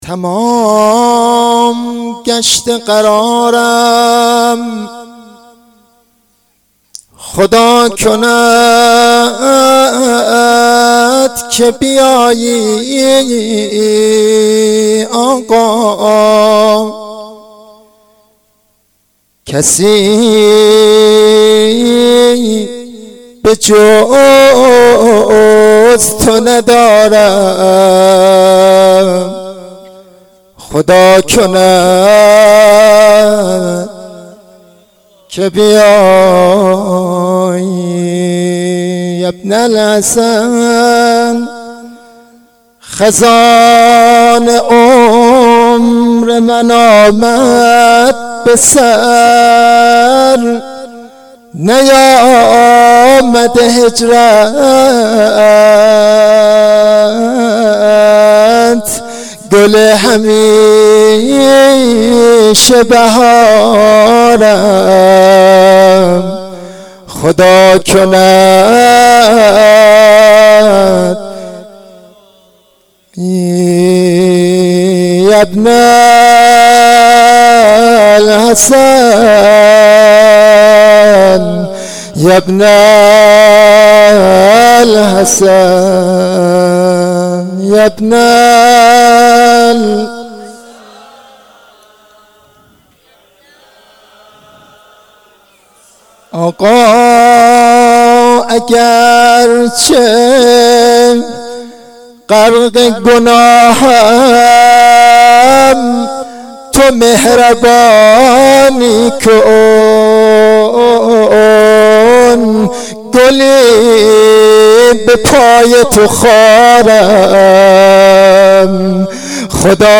مناجات شام غریبان93